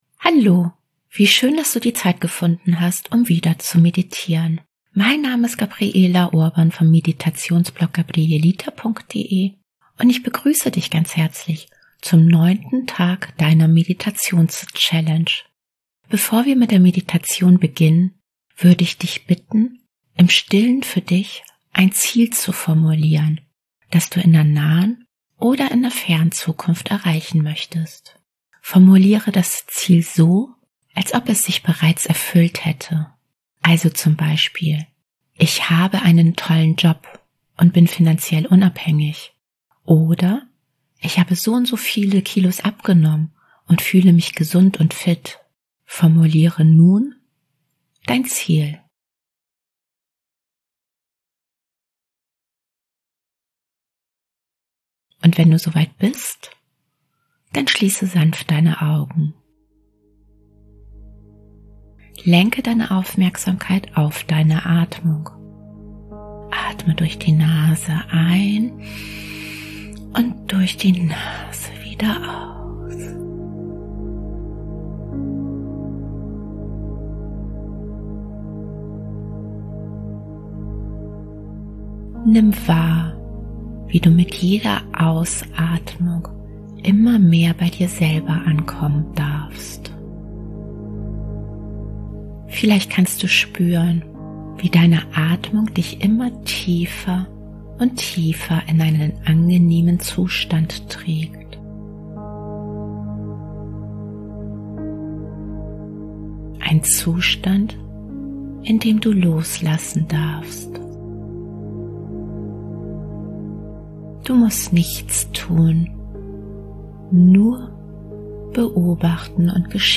Traumreisen & geführte Meditationen